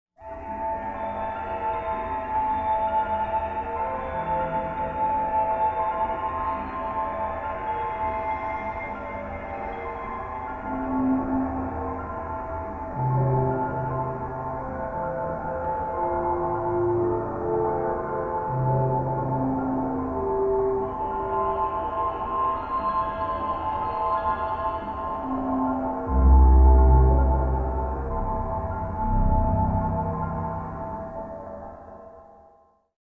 Ambience1 >>
8: Lägg till bakgrundsljud (ambience) på kanalerna under.
ambience1.mp3